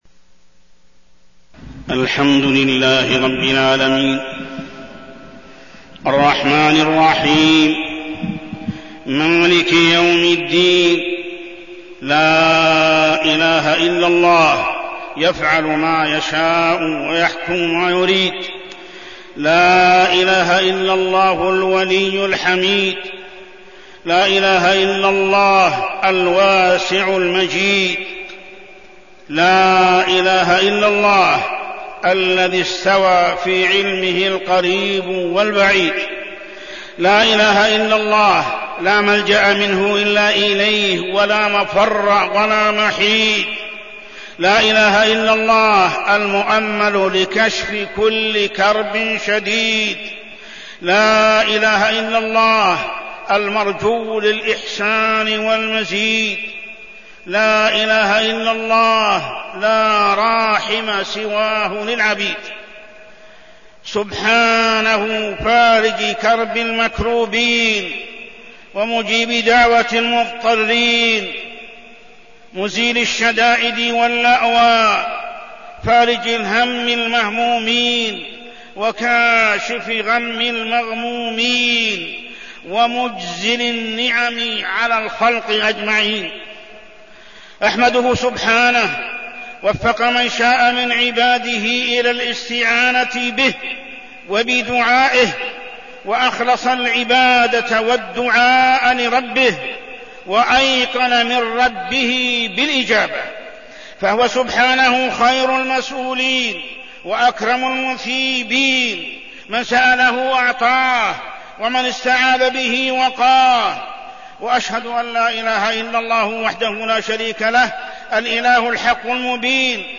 تاريخ النشر ٧ شعبان ١٤١٩ هـ المكان: المسجد الحرام الشيخ: محمد بن عبد الله السبيل محمد بن عبد الله السبيل الإستغفار من أسباب نزول المطر